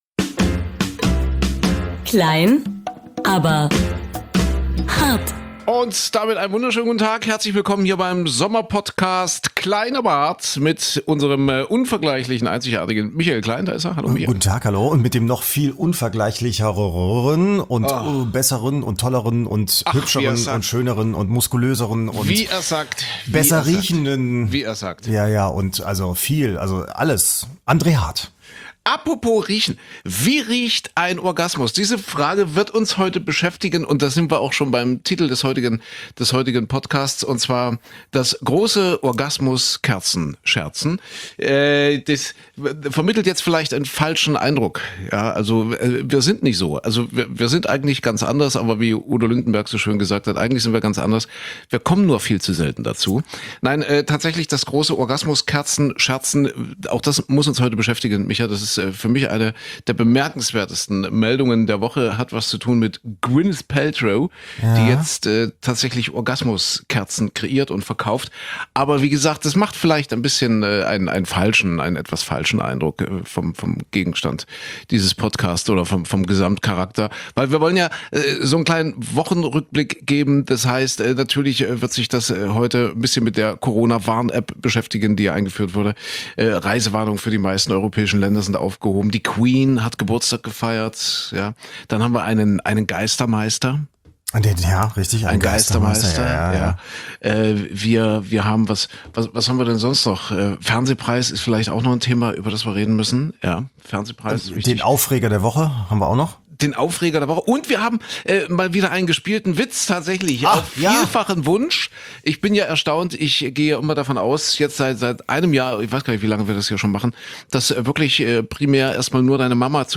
Immer mit einem leichten Augenzwinkern und Diskussionspotential. Dabei nehmen sie kein Blatt vor den Mund.